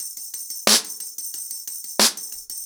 ROOTS-90BPM.1.wav